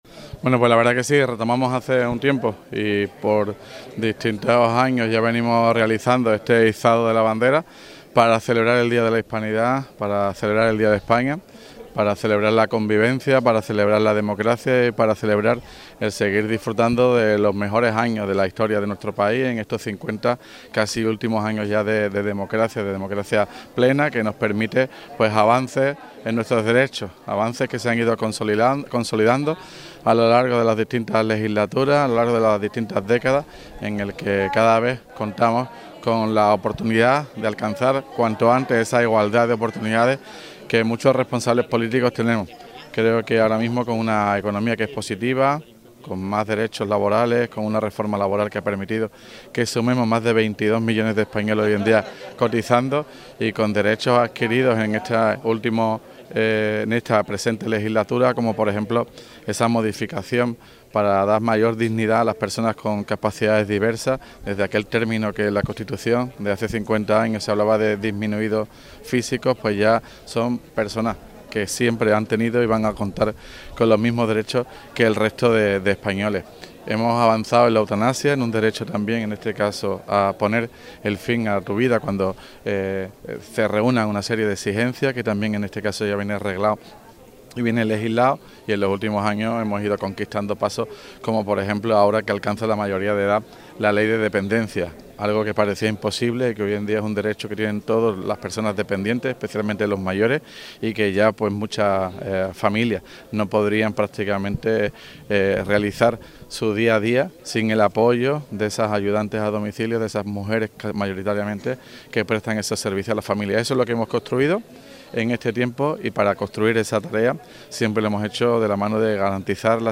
El alcalde de San Roque, Juan Carlos Ruiz Boix, ha presidido el acto que, con motivo del Día de la Fiesta Nacional de España, se ha celebrado al mediodía de hoy, domingo, en la Plaza de Armas. El primer edil fue el encargado del izado de la bandera nacional mientras sonaba el himno nacional.
DIA_HISPANIDAD_TOTAL_ALCALDE.mp3